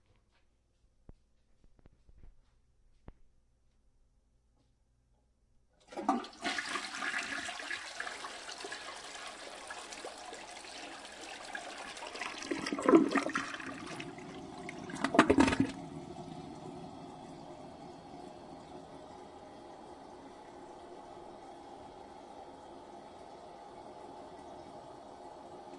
冲厕
描述：挪威人在小浴室里冲马桶的声音被间谍麦克风捕捉到。
标签： 环境 - 声音的研究 冲水 马桶
声道立体声